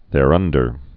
(thâr-ŭndər)